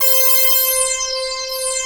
BIG LEADC5-R.wav